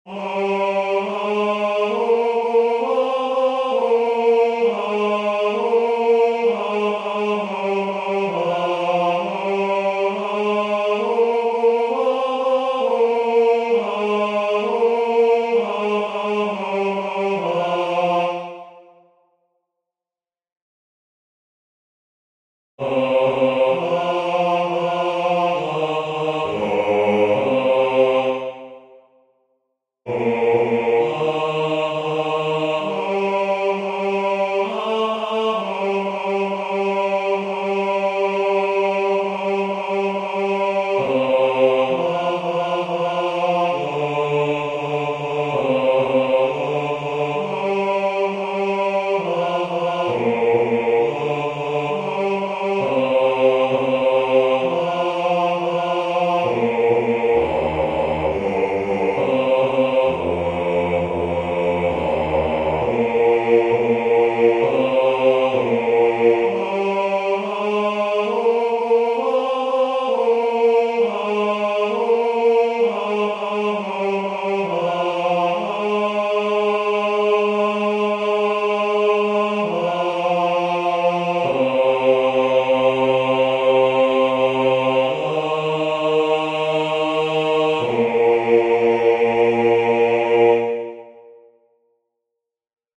Basse 1